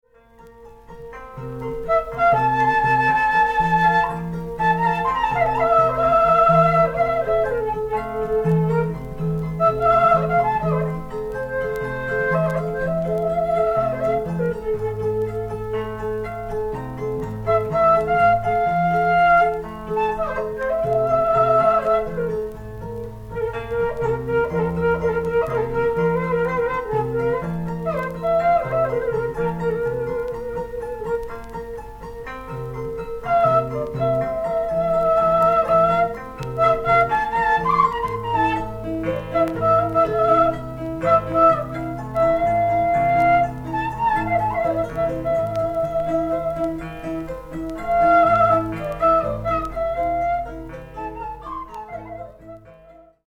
18弦の舟形ツィター『カチャピ』と、循環呼吸によって切れ目なく吹き鳴らされる『スリン』と呼ばれる竹笛による合奏を収録。
民族音楽